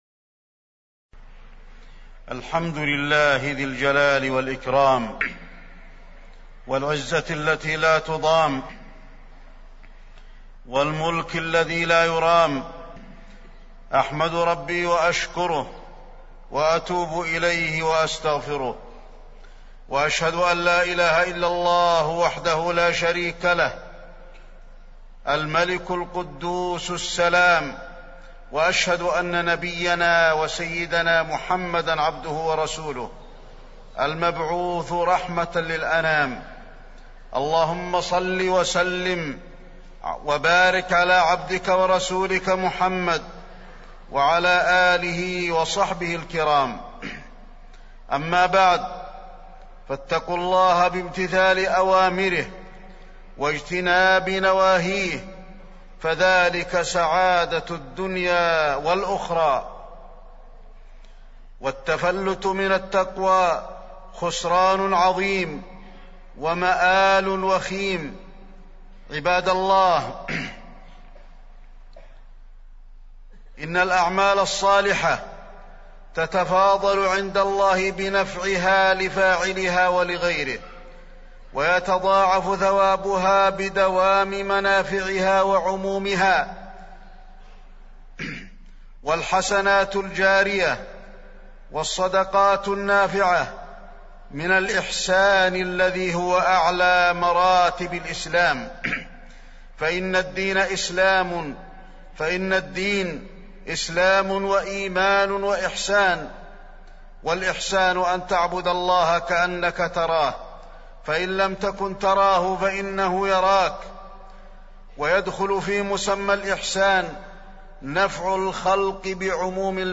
تاريخ النشر ٢٢ صفر ١٤٢٦ هـ المكان: المسجد النبوي الشيخ: فضيلة الشيخ د. علي بن عبدالرحمن الحذيفي فضيلة الشيخ د. علي بن عبدالرحمن الحذيفي الإحسان